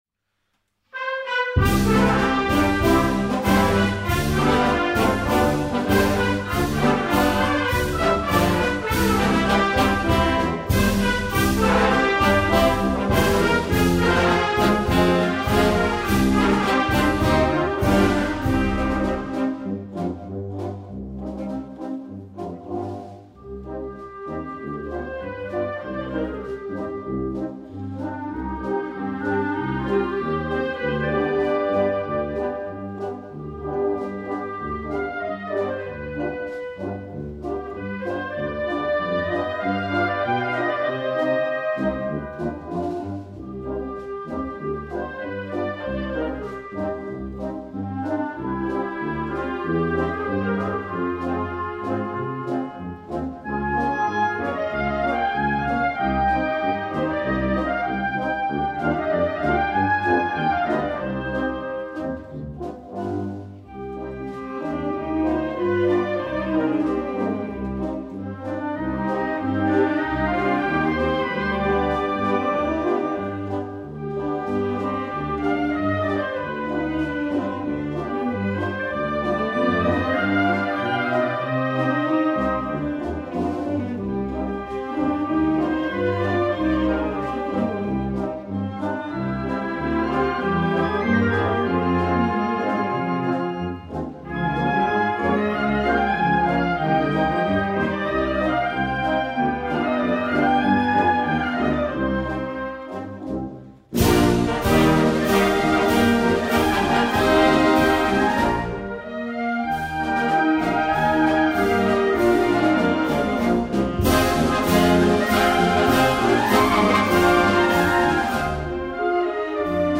Himne
himno_cami_nou.mp3